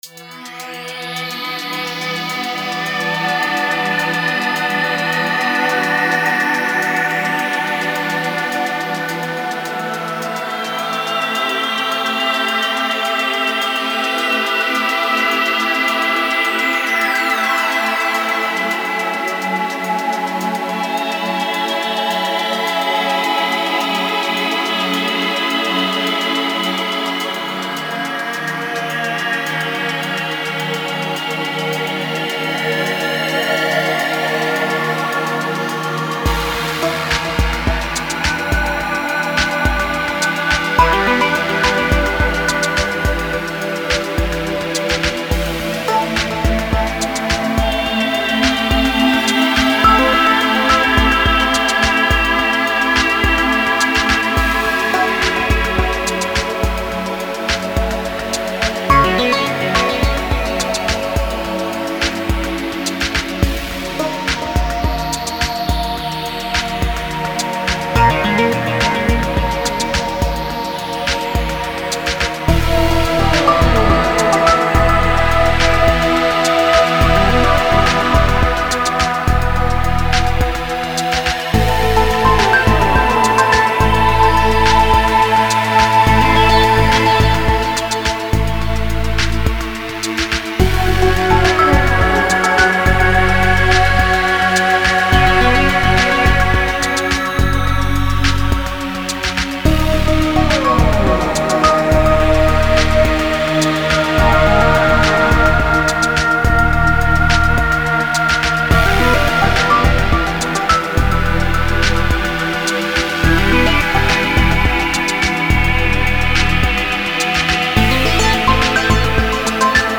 Genre: Ambient,Electronic.